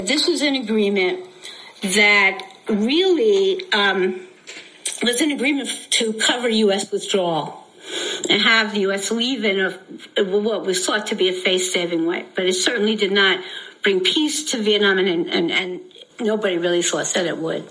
ສຽງ 3- ນັກປະຫວັດສາດອາເມຣິກັນ ກ່າວກ່ຽວກັບຄວາມຫລົ້ມແຫລວ ຂອງສັນຍາປາຣີ